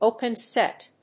音節pen sét